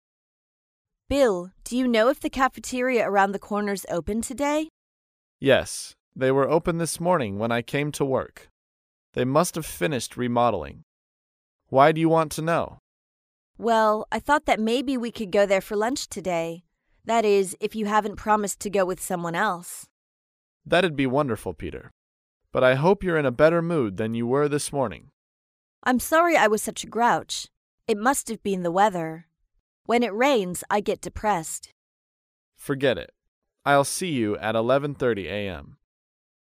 在线英语听力室高频英语口语对话 第46期:共进午餐的听力文件下载,《高频英语口语对话》栏目包含了日常生活中经常使用的英语情景对话，是学习英语口语，能够帮助英语爱好者在听英语对话的过程中，积累英语口语习语知识，提高英语听说水平，并通过栏目中的中英文字幕和音频MP3文件，提高英语语感。